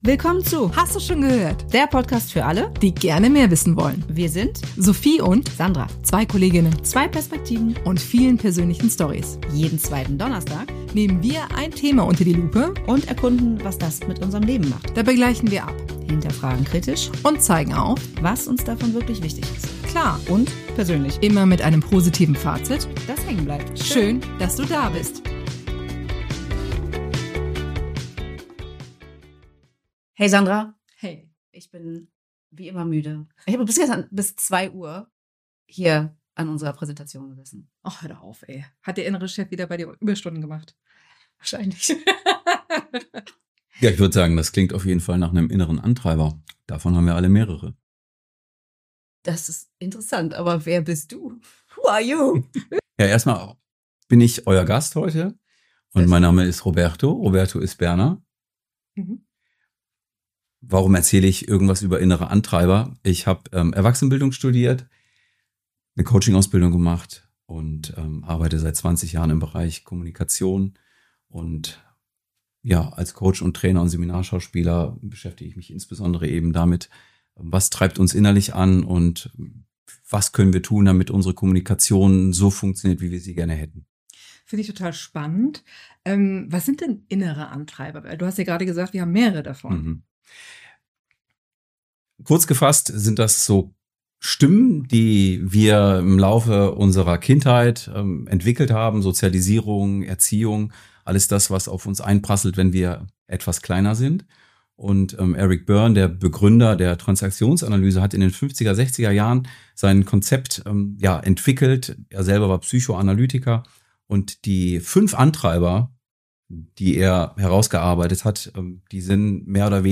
Wie sich das bei den Ladies in der Zusammenarbeit auswirkt, hört ihr in dieser Folge, die in der Tat wissenschaftliche Elemente sehr humorvoll erläutert.